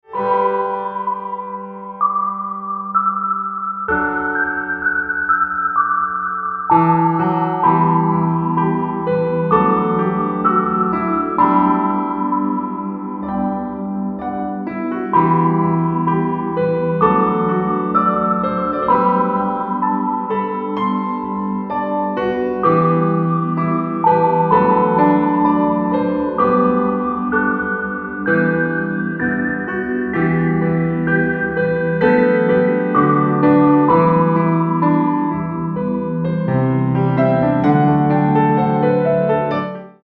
レコーディングスタジオ : JEO